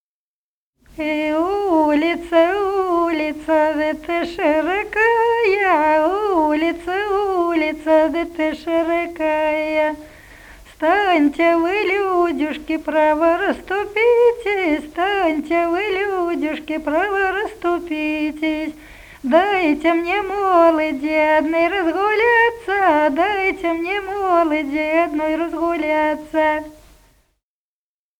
Народные песни Смоленской области
«Эй, улица, улица» (плясовая).